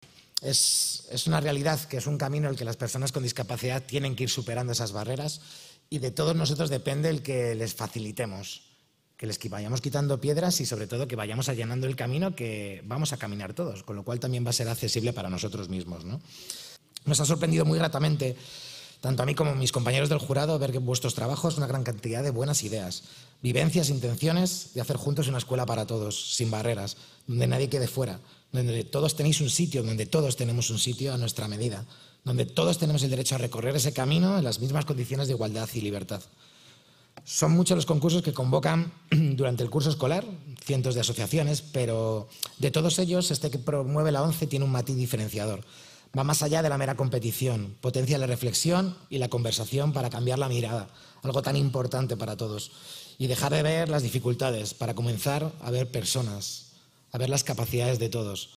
dijo a los escolares formato MP3 audio(1,12 MB), en calidad de miembro del jurado autonómico de Madrid, en el acto de entrega de premios.